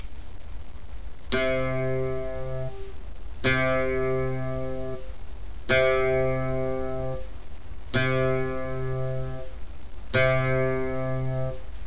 El sonido de cada cuerda afinada deberia de sonar asi:
Segunda(Do)
afinacion_do.wav